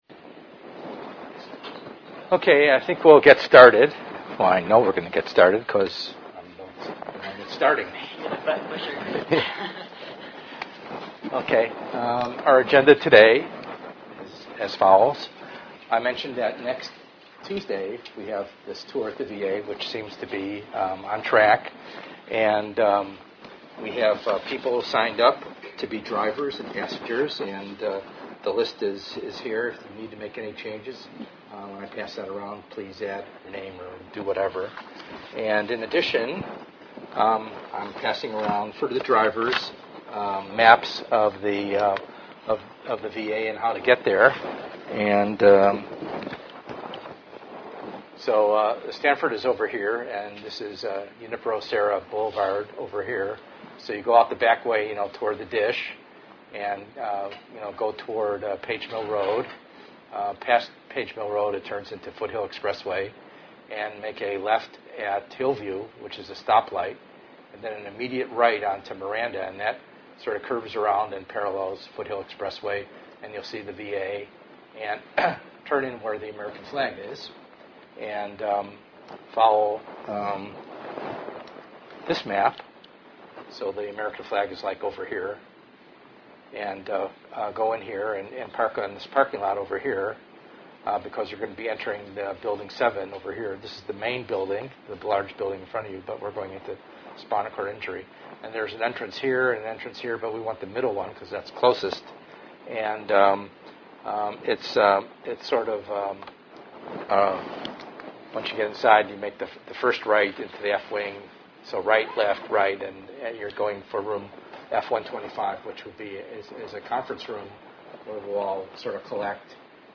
ENGR110/210: Perspectives in Assistive Technology - Lecture 3a